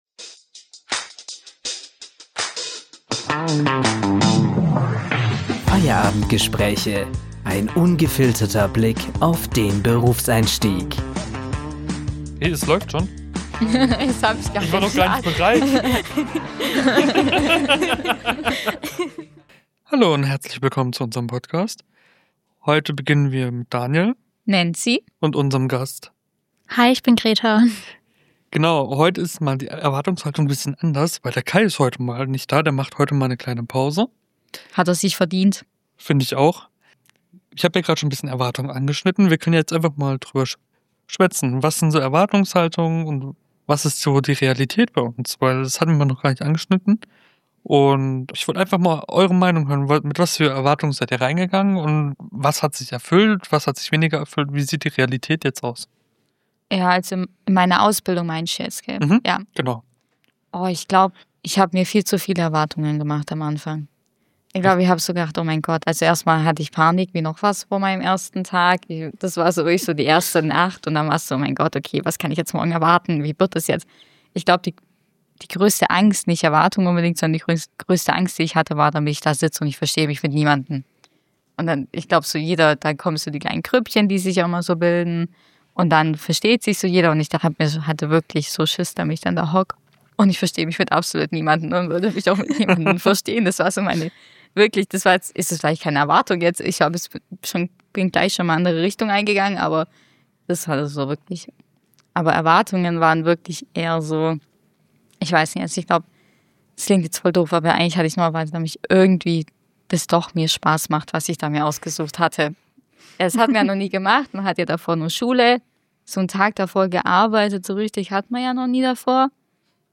Gemeinsam sprechen die drei über eines der nervenaufreibendsten Themen überhaupt: die eigenen Erwartungen vor dem Start in die Ausbildung oder das Studium – und was am Ende wirklich davon übrig bleibt.